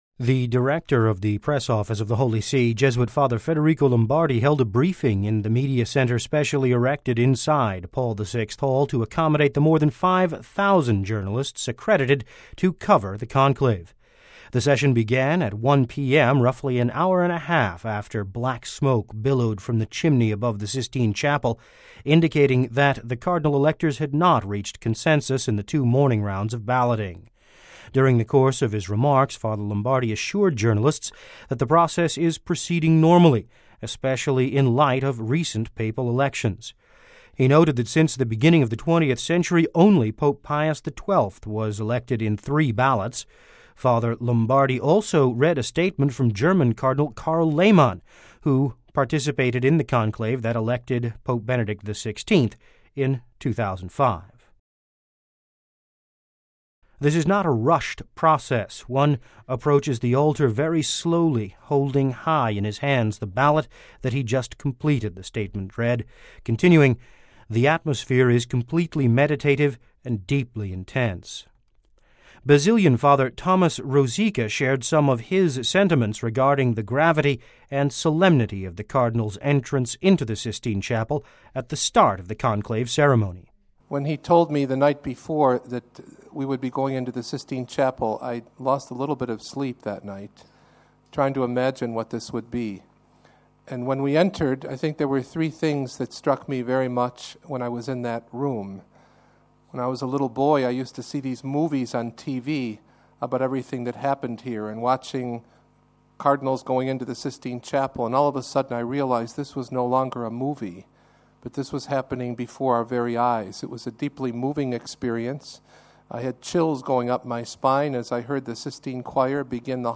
Fr Lombardi SJ: conclave press briefing
(Vatican Radio) The Director of the Press Office of the Holy See, Fr. Federico Lombardi, SJ, held a briefing in the media centre specially erected inside the Paul VI Hall to accommodate the more than 5 thousand journalists accredited to cover the Conclave.